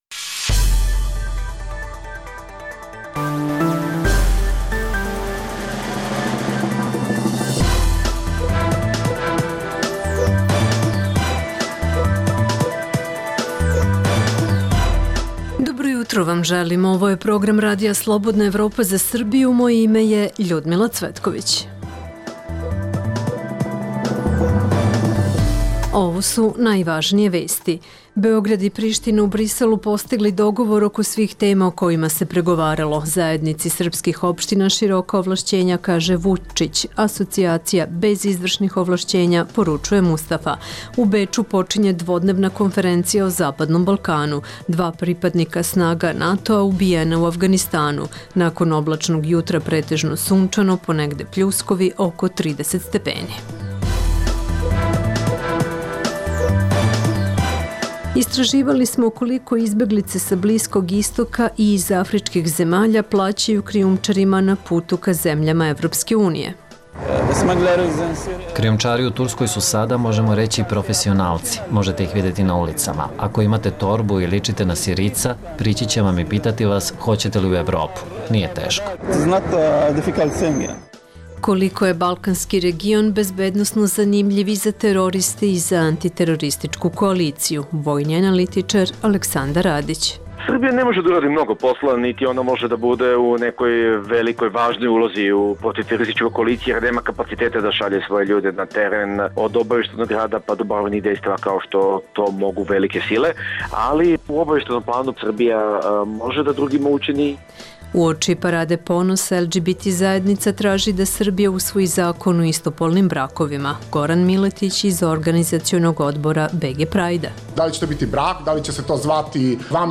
- Beograd i Priština u Briselu postigli dogovor oko svih tema o kojima se pregovaralo, uključujući i Zajednicu srpskih opština. Čućete šta kažu premijeri Srbije i Kosova.
Uključujemo izveštača RSE.